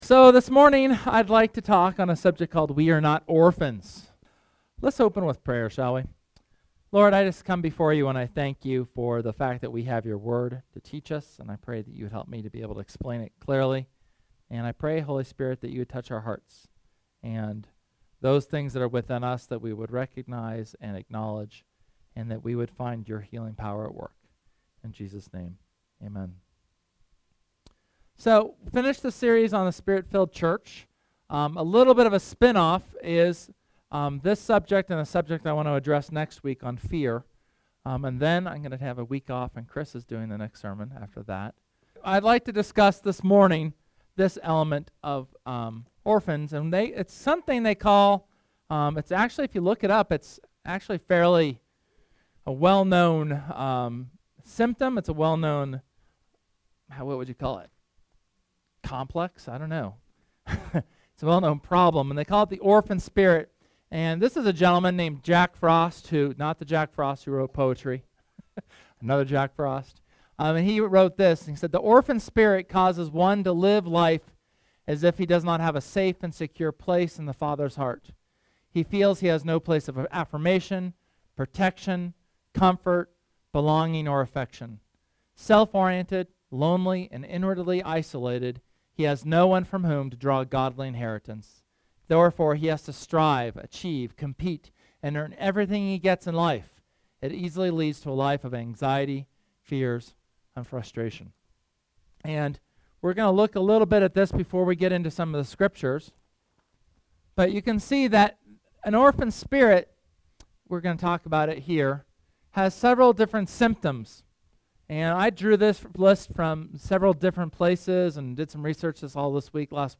SERMON: We are not orphans